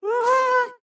Minecraft Version Minecraft Version snapshot Latest Release | Latest Snapshot snapshot / assets / minecraft / sounds / mob / happy_ghast / ambient7.ogg Compare With Compare With Latest Release | Latest Snapshot